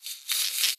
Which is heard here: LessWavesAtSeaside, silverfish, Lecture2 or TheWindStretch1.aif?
silverfish